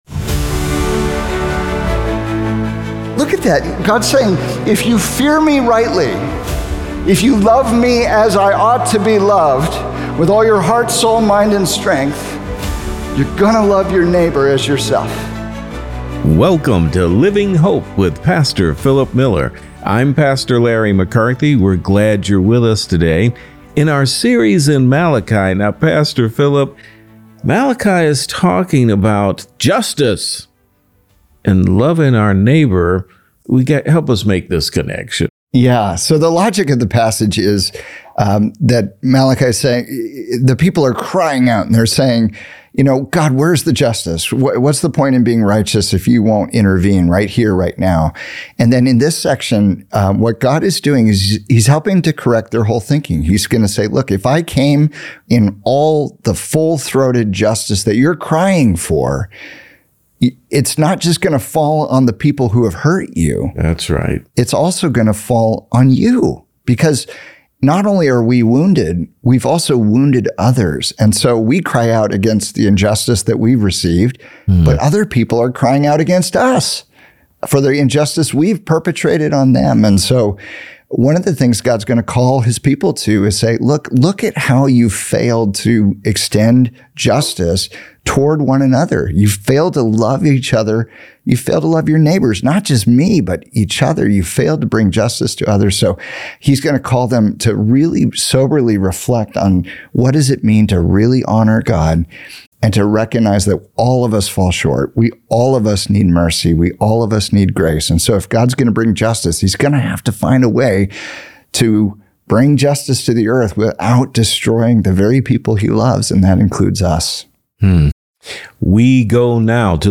Why You Cannot Love God and Ignore Your Neighbor | Radio Programs | Living Hope | Moody Church Media